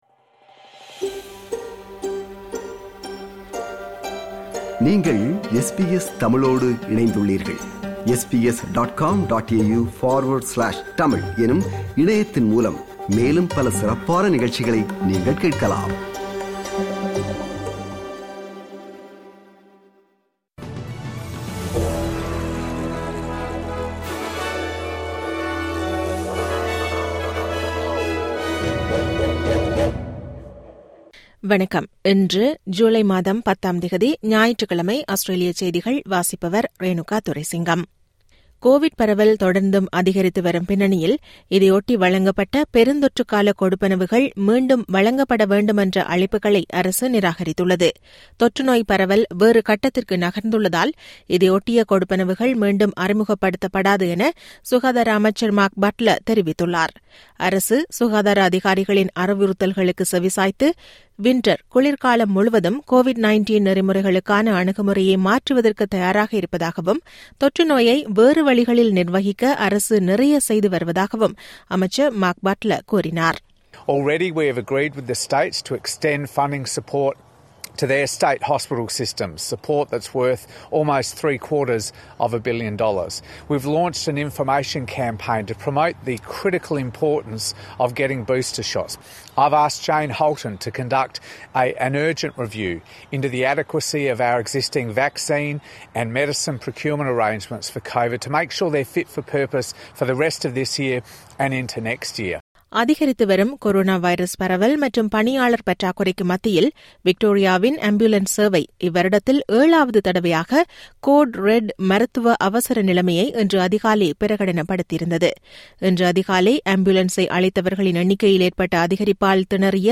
Australian news bulletin for Sunday 10 July 2022.